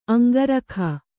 ♪ aŋgarakha